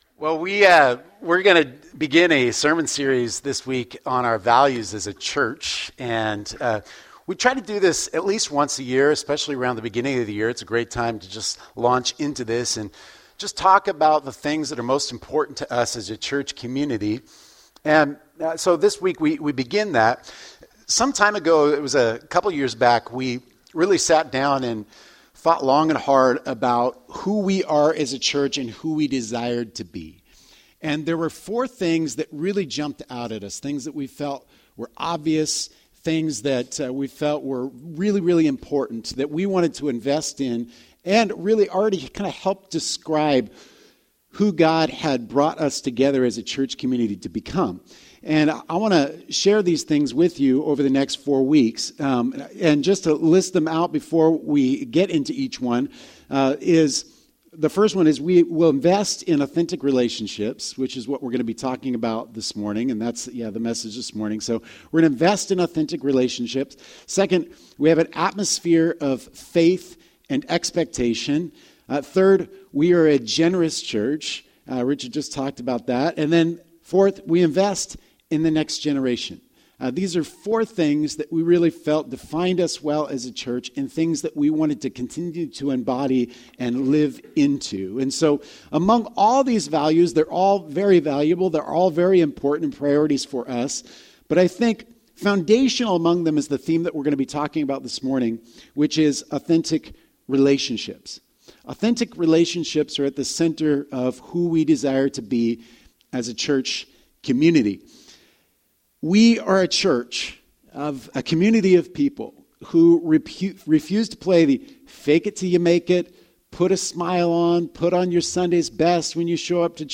A few years ago, we set out to put words to those passions and we compiled a list of our core values. During this sermon series, we will be sharing them with you!